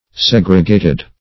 Segregate \Seg"re*gate\, v. t. [imp.